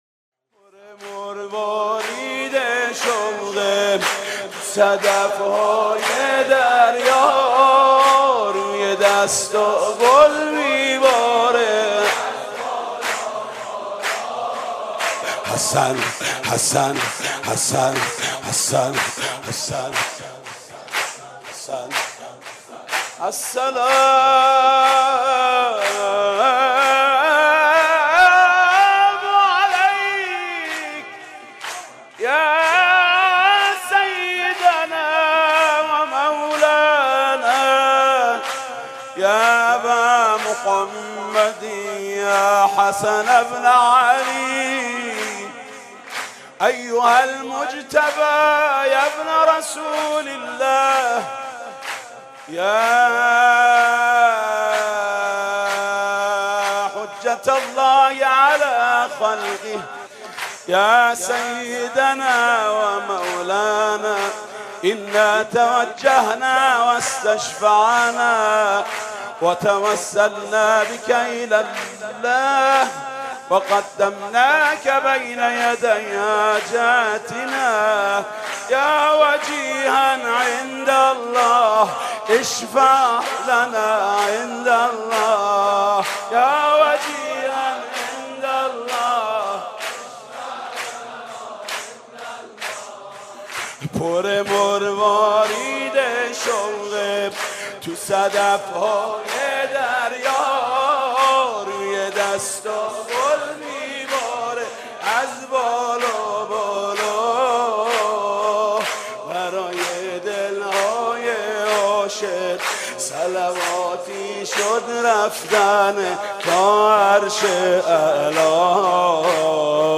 سرود: پر مروارید شوق تو صدف های دریا